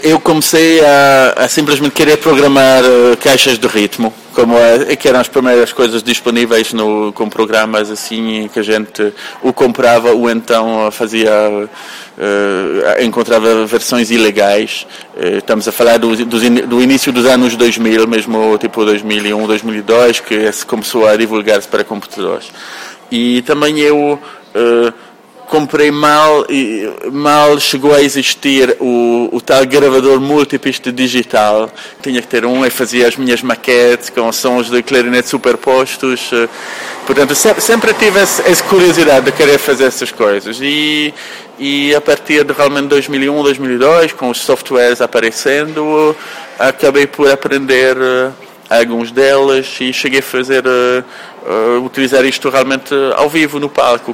O clarinetista